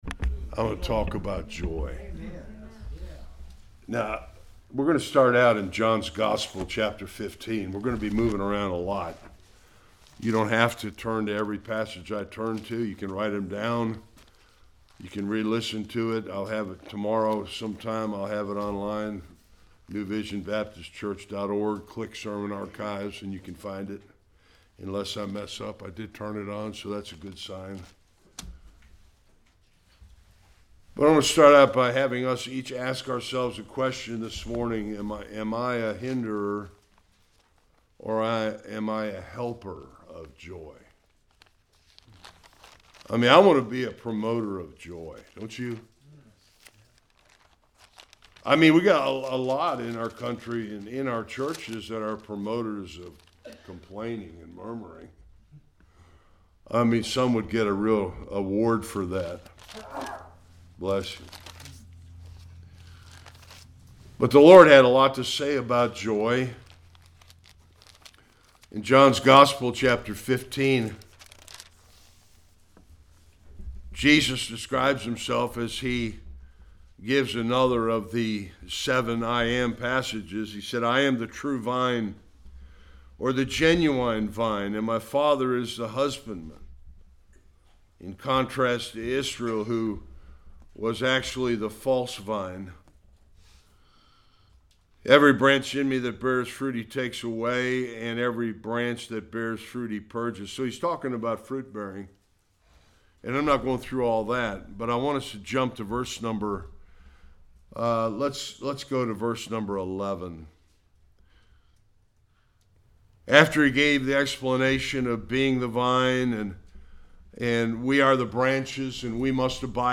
Various Passages Service Type: Sunday Worship Real joy is not just happiness.